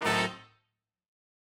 GS_HornStab-Adim.wav